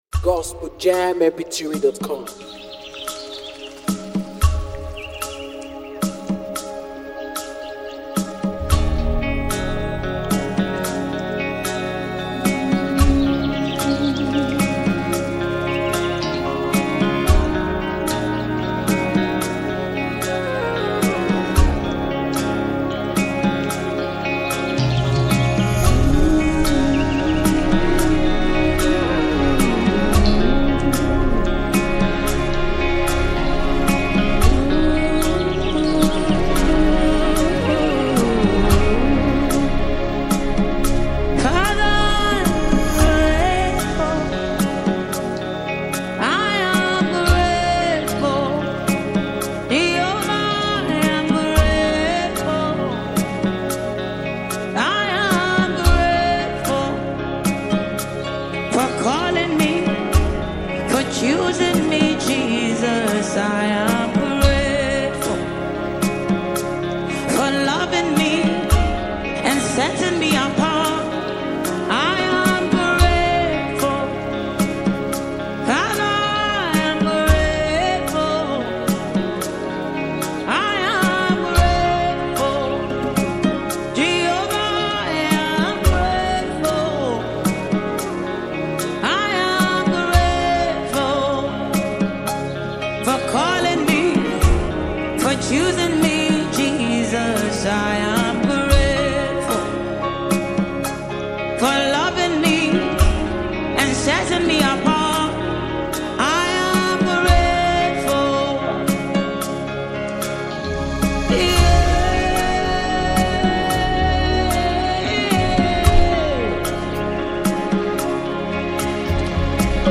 Christian worship and thanksgiving anthem